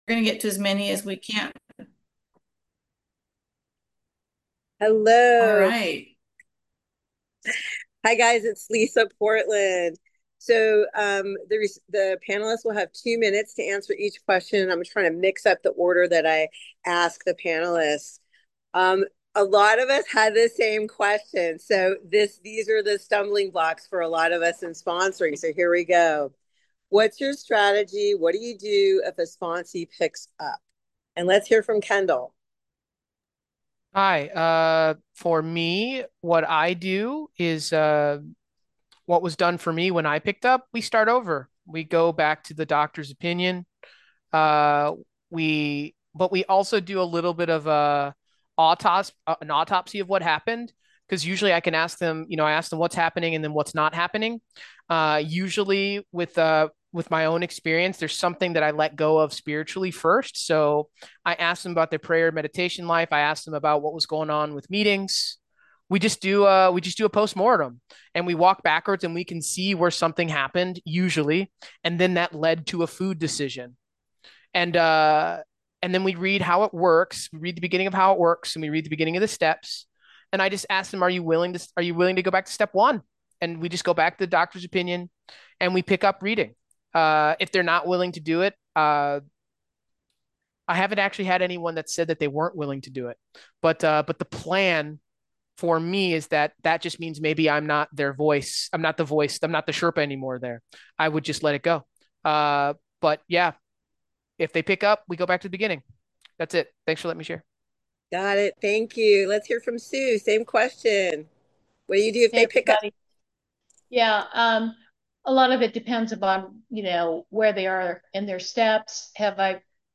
2025-08-17: Part 2: 2025 Sponsorship Day Workshop Q&A – OA Foot Steps